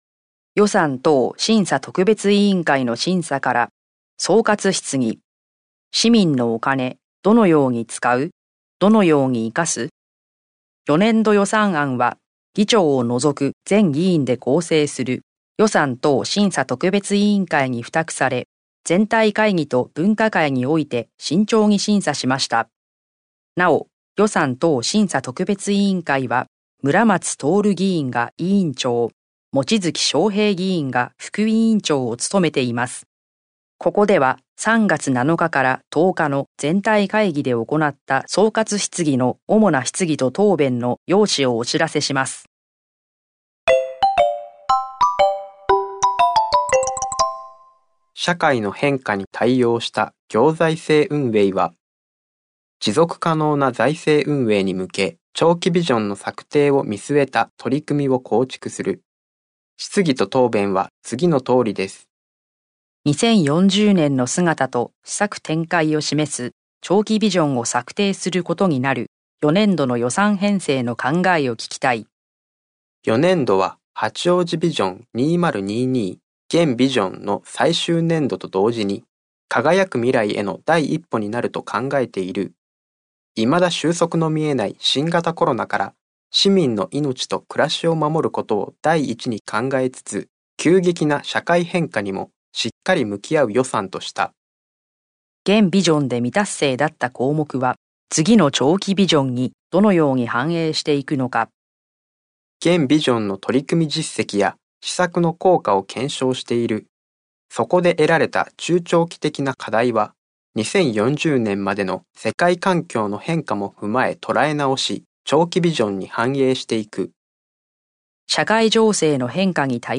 声の市議会だより」は、視覚に障害のある方を対象に「八王子市議会だより」を再編集し、点字や音声にしたものです。